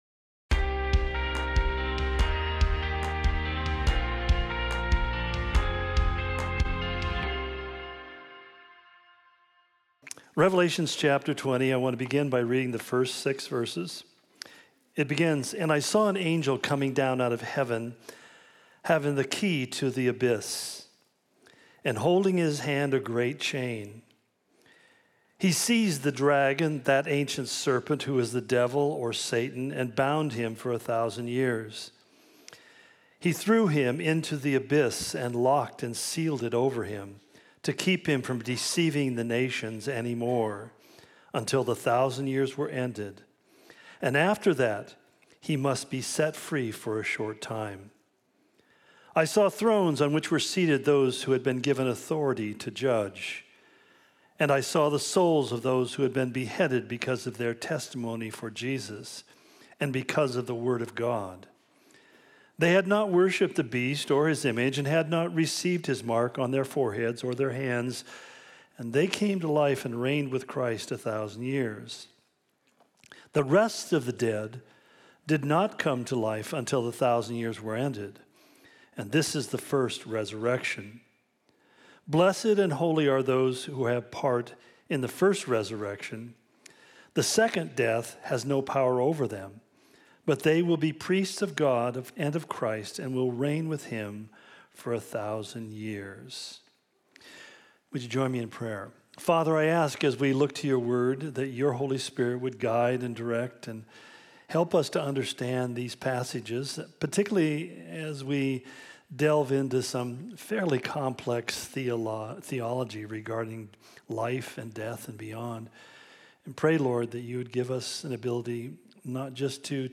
Calvary Spokane Sermon Of The Week podcast To give you the best possible experience, this site uses cookies.